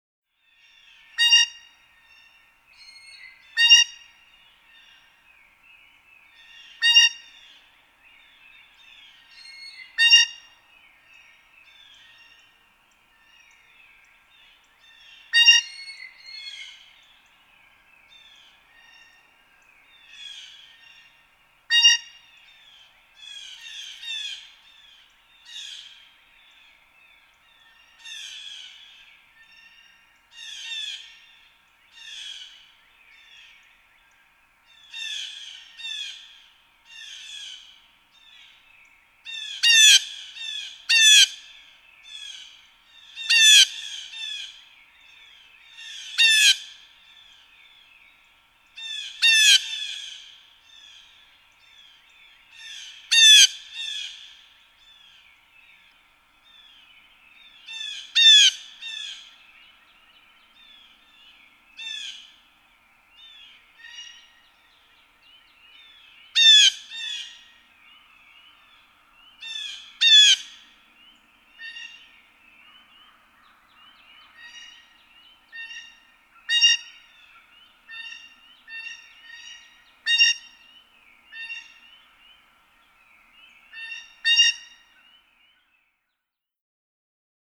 Blue jay
Blue jays often call before departing their overnight roost; listen here how the nearby jay matches the calls of other jays in the neighborhood (see book text for details).
Amherst, Massachusetts.
460_Blue_Jay.mp3